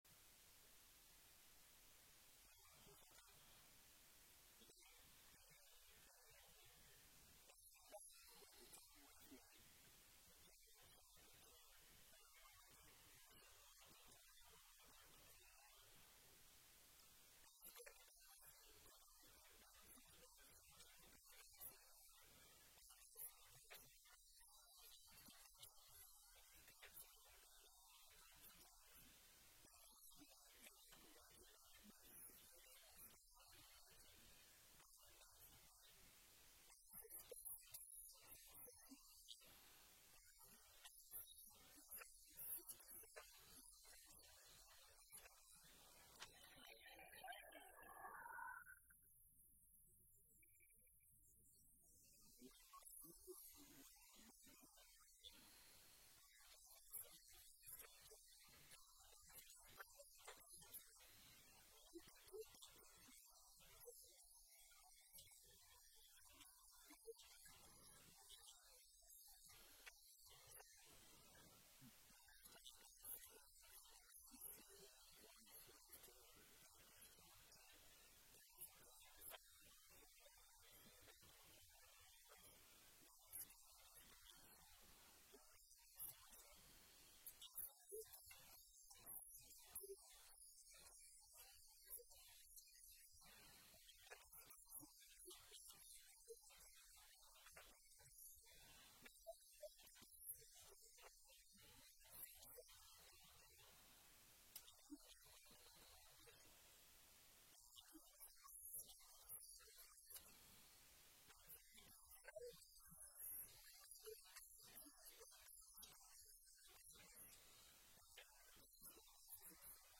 June 8, 2025 Sermon Audio.mp3